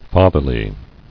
[fa·ther·ly]